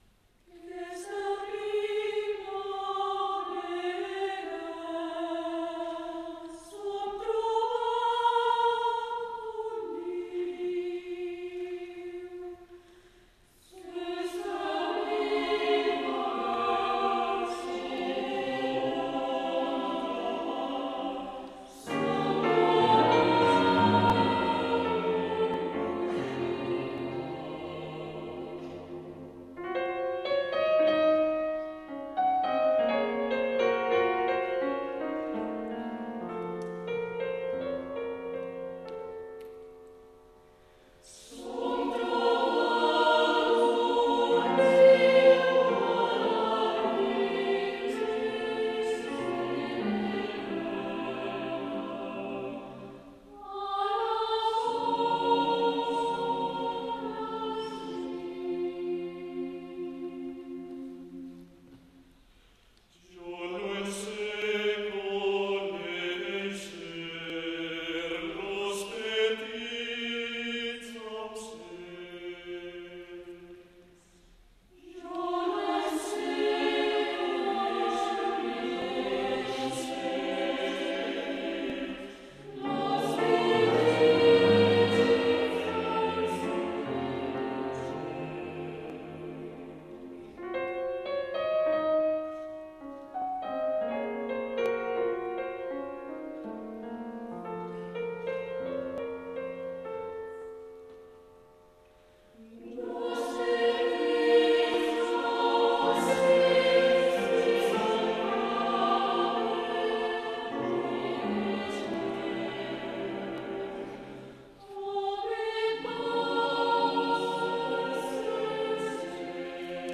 Mi 3 - Do 4